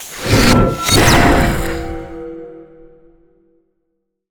dark_magic_conjure_blast_1.wav